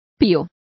Complete with pronunciation of the translation of cheeps.